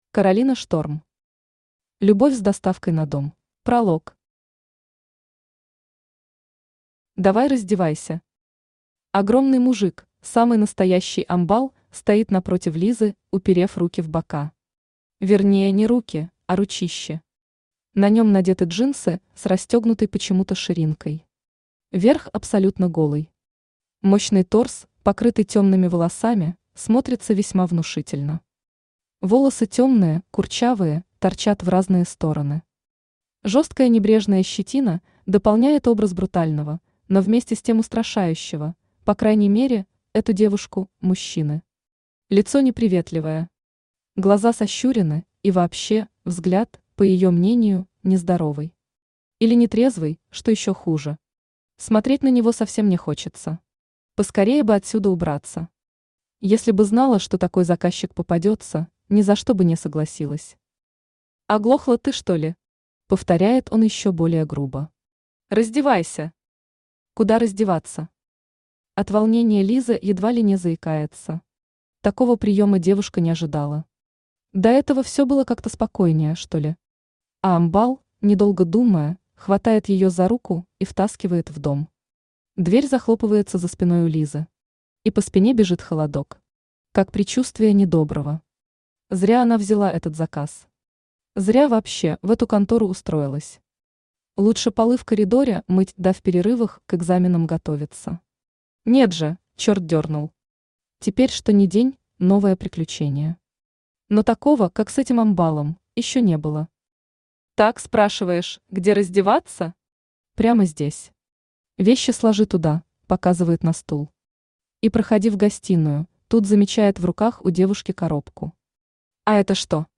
Aудиокнига Любовь с доставкой на дом Автор Каролина Шторм Читает аудиокнигу Авточтец ЛитРес.